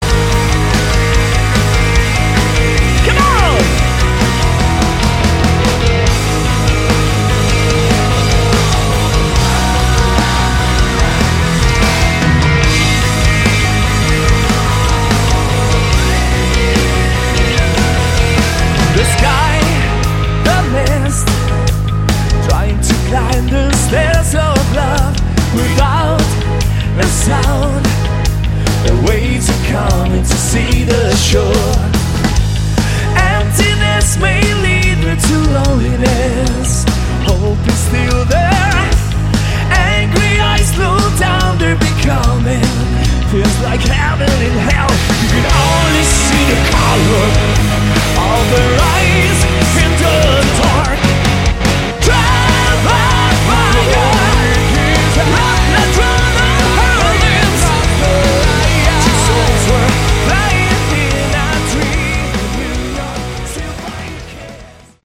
Category: Melodic Metal
vocals
guitar
bass
backing vocals
keyboards
drums